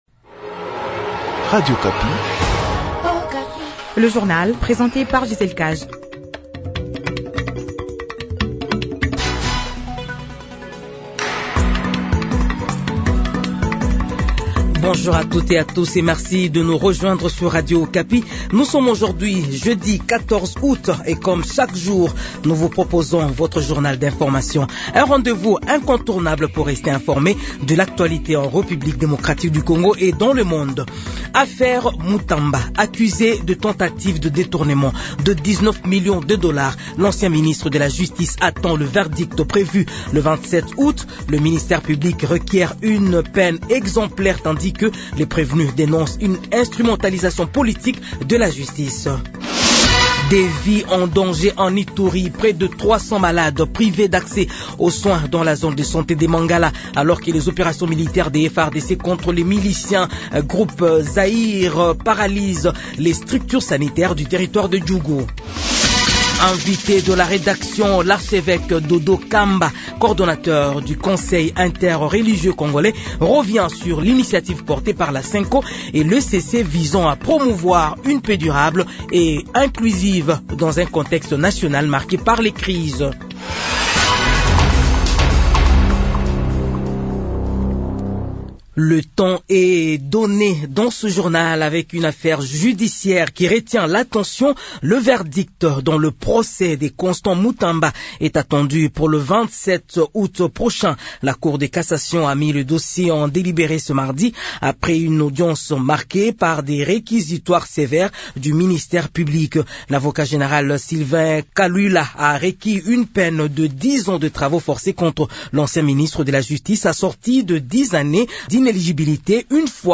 Edition du matin du jeudi 14 aout 2025